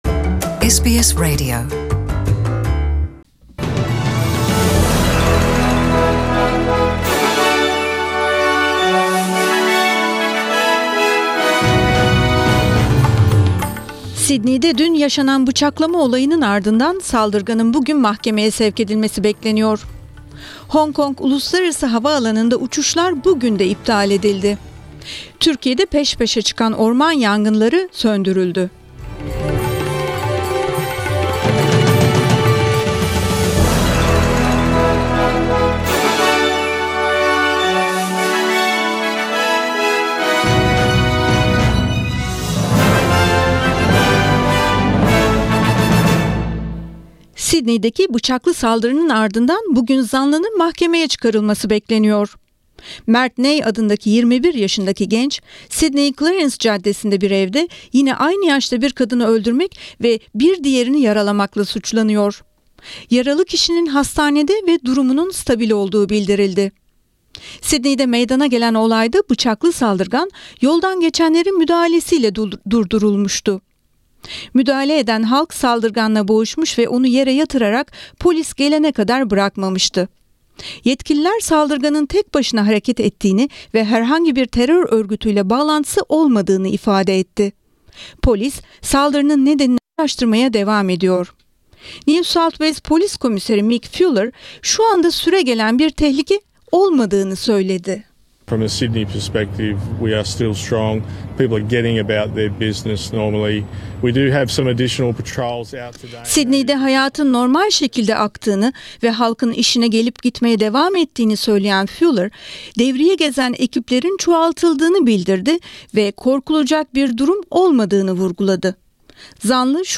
SBS Turkish News